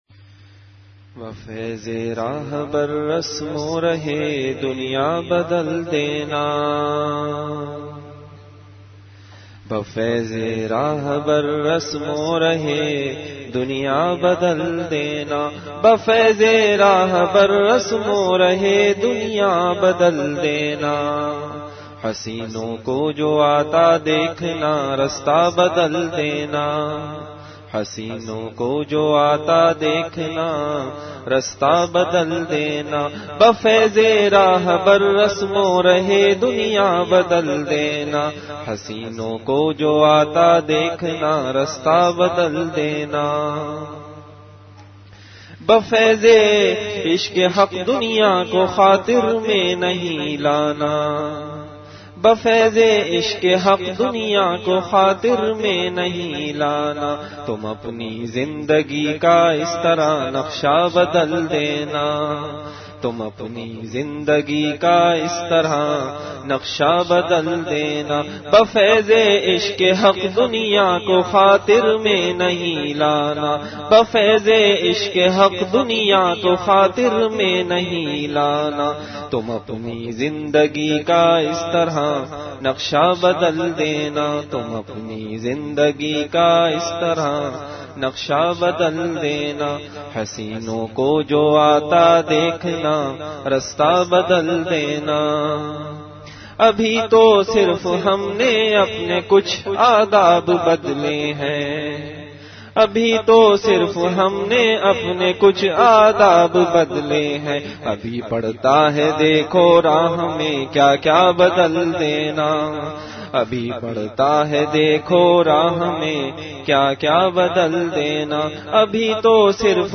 Delivered at Home.
Majlis-e-Zikr · Home Ehle Haq Se Musalsal Talluq